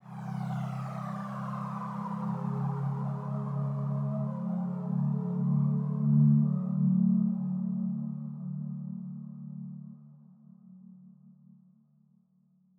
4beat_sweep.wav